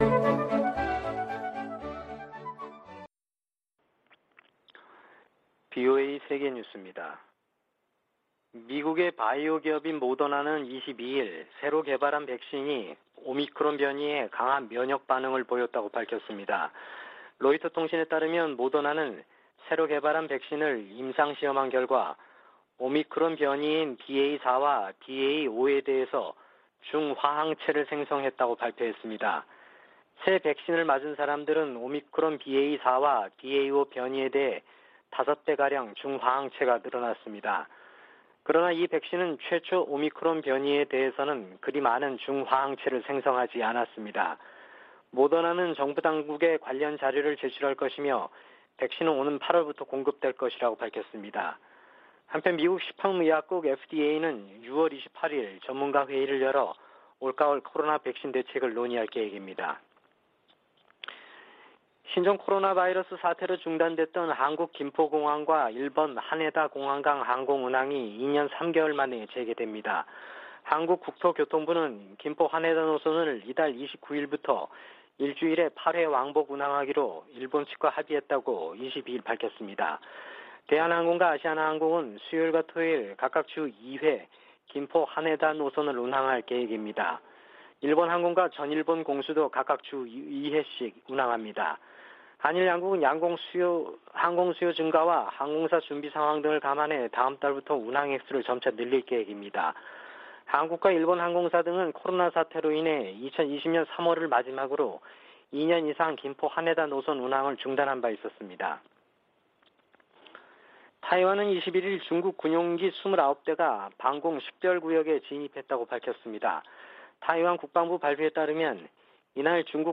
VOA 한국어 아침 뉴스 프로그램 '워싱턴 뉴스 광장' 2022년 6월 23일 방송입니다. 미 국무부가 미한 상호방위 역량 강화와 대북 대화 추구 등 주한 미국대사관의 향후 4년 목표를 공개했습니다. 미국 정부가 한반도를 제외한 모든 지역에서 대인지뢰 사용과 생산 등을 금지한다고 밝혔습니다. 미 국방부가 북한의 탄도미사일 대응에 적합하다는 평가를 받고 있는 해상요격기 SM-3 미사일 생산 업체를 선정했습니다.